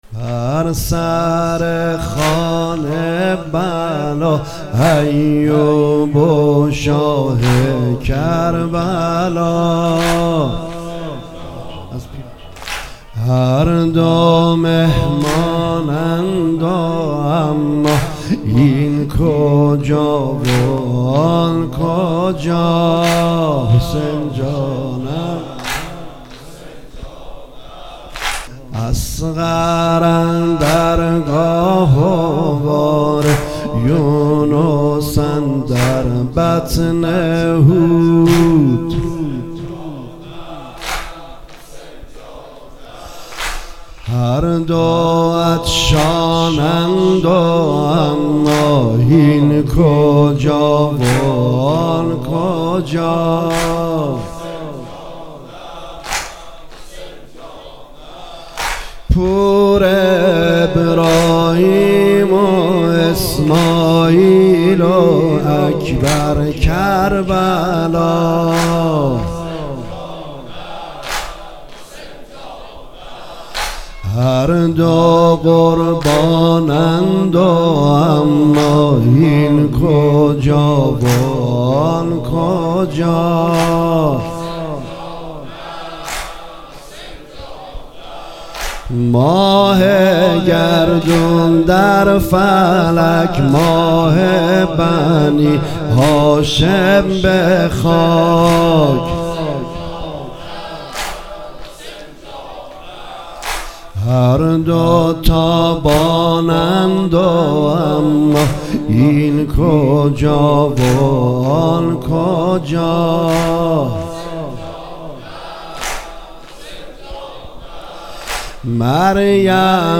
بر سر خوان بلا نریمان پناهی | هیئت جاماندگان اربعین کربلا | تهیه شده توسط خانه هنرپلان 3